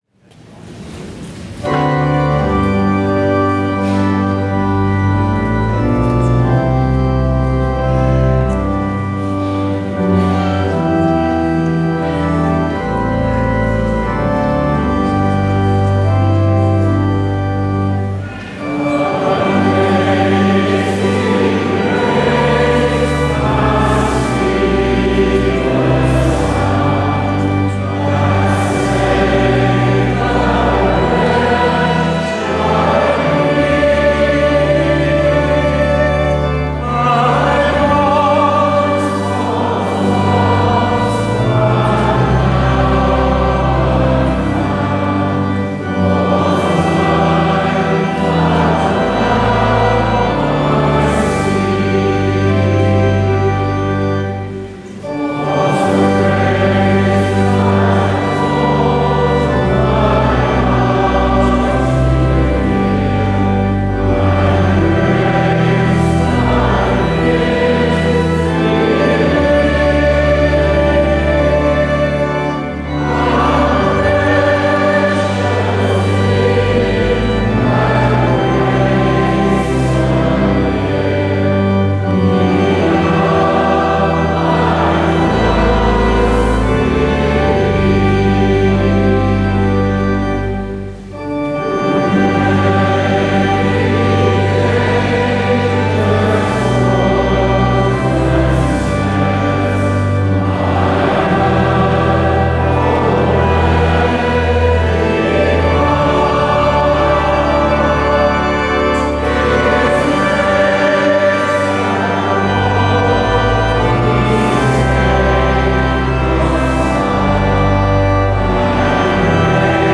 Diocesan Rite of Election
First Sunday of Lent
Hymn – Amazing Grace!